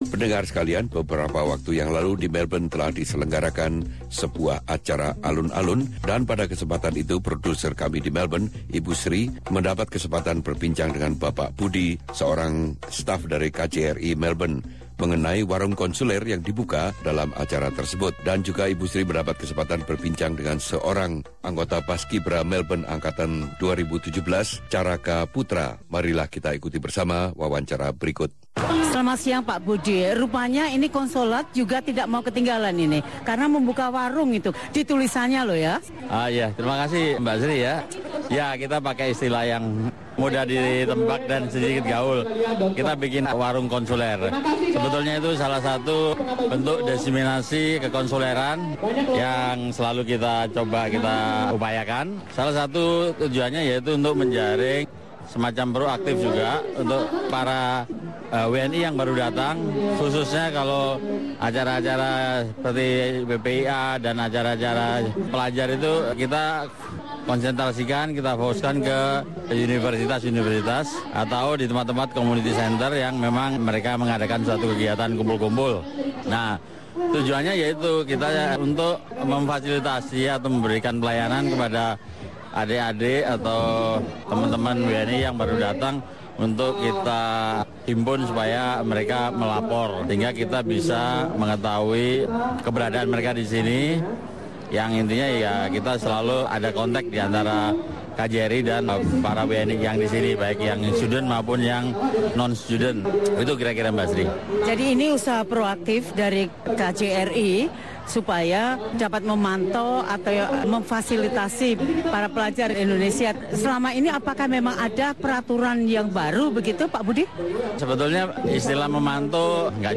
Dalam acara Alun Alun baru-baru ini di Melbourne